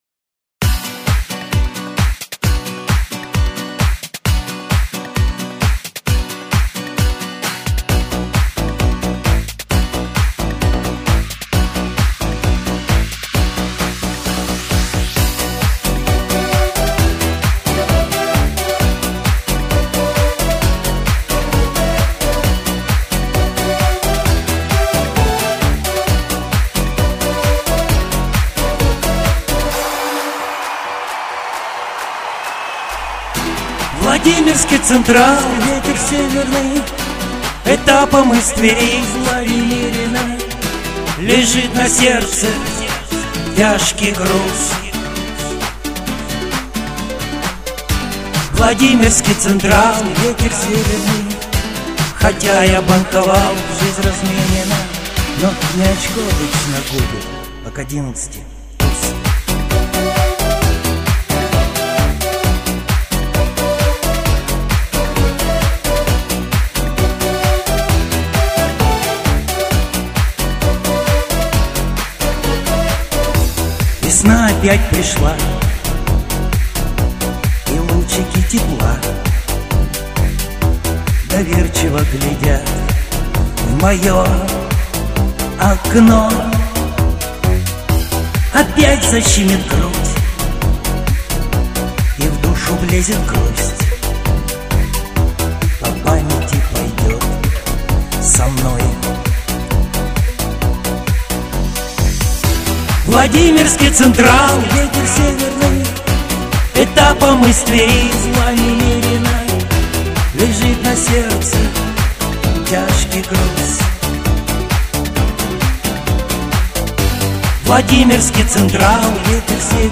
Голос - голос приятный, мягкий, лиричный.